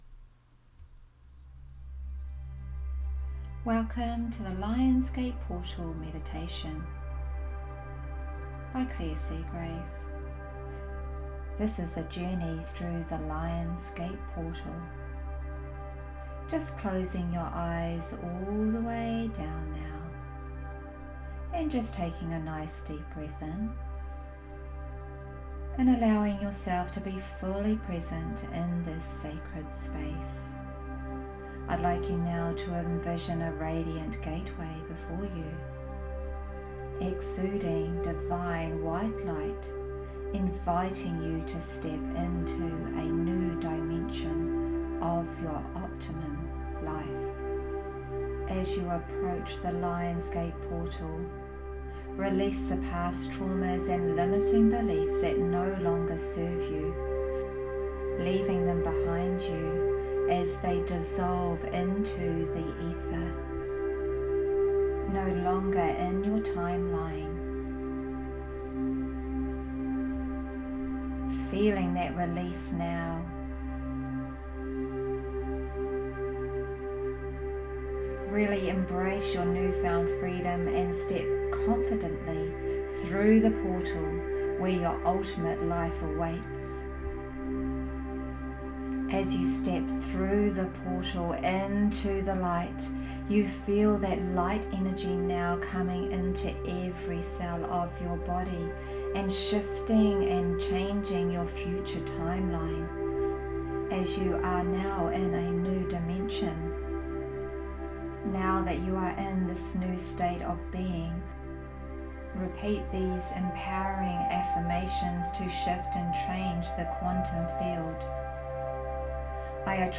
Here is a wonderful powerful manifestation meditation for the